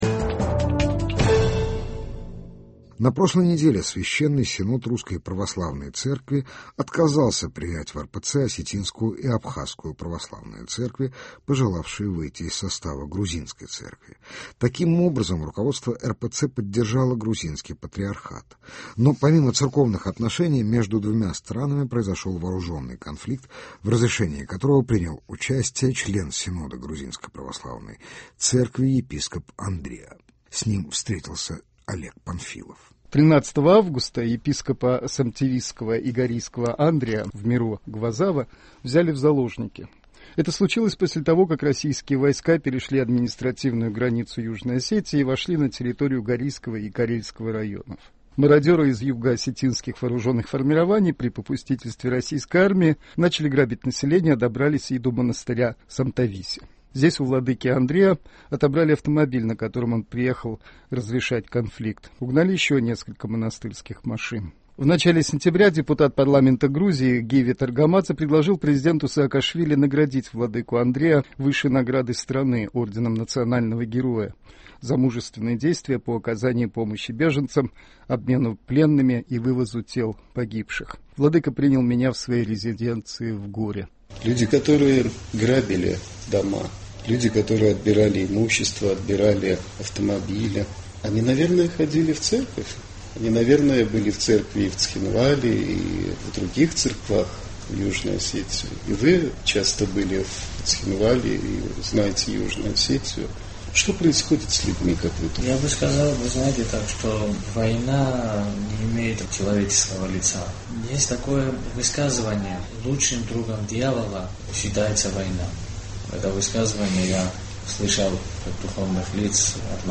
Новые лица Грузии: интервью с епископом Самтависским и Горийским Андриа.